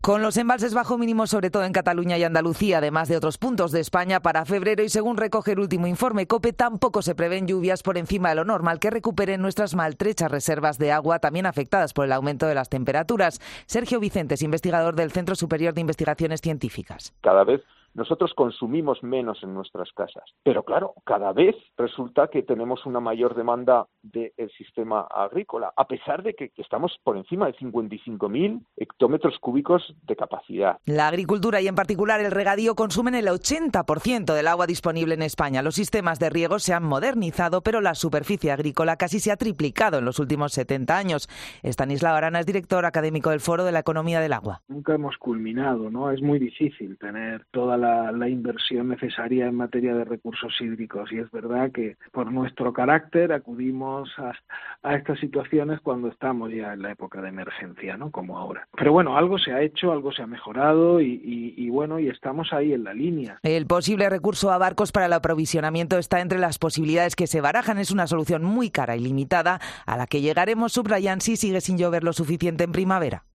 Informe COPE sobre la sequía: Cataluña y Andalucía, al límite por la sequía